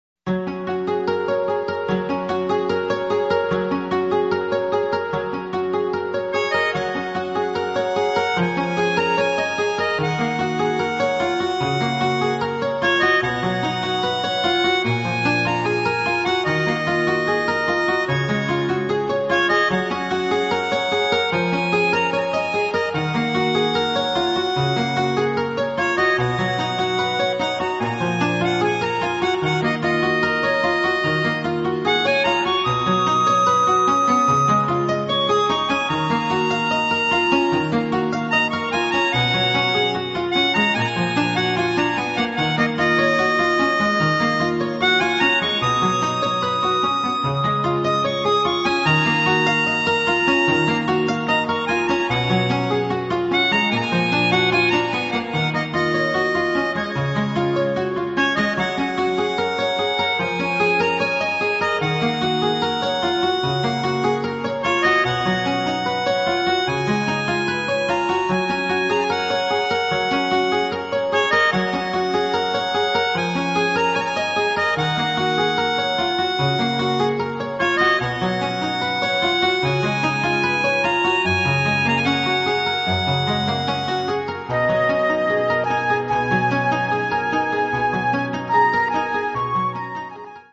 管楽器または弦楽器のソロとピアノ